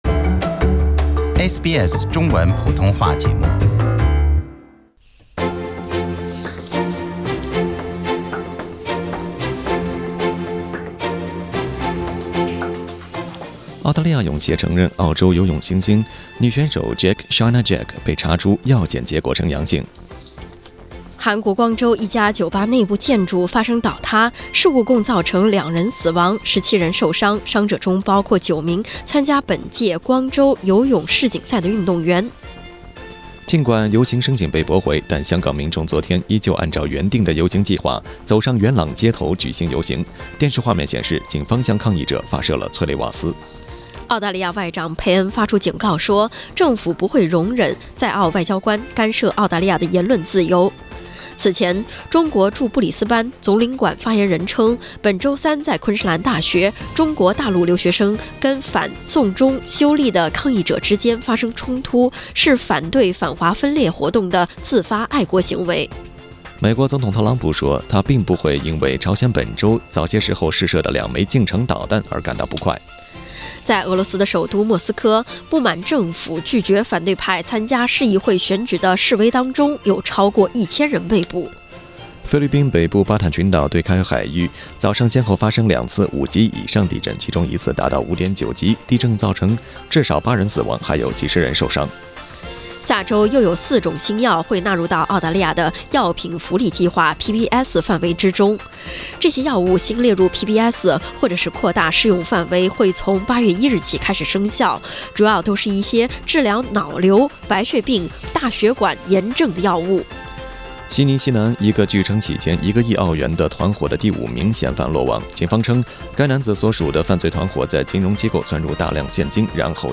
SBS早新闻（7月28日）